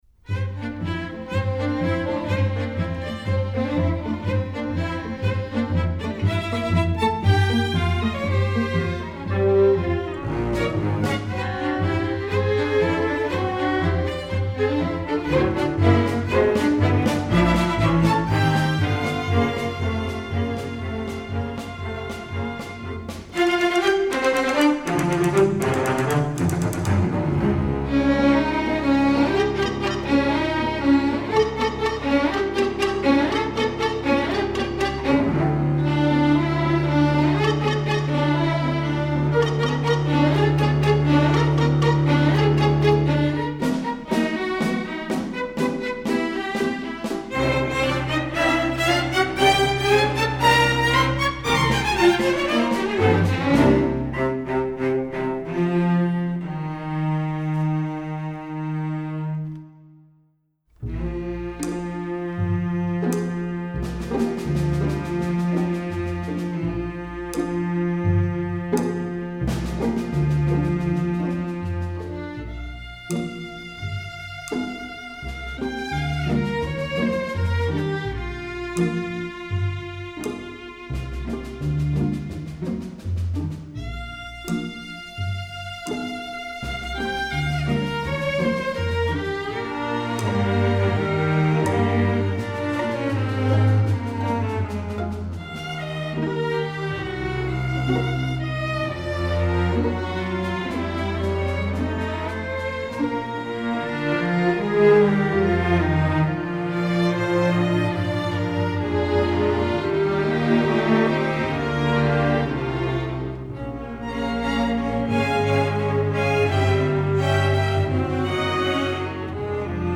Instrumentation: string orchestra
masterwork arrangement, film/tv, movies, american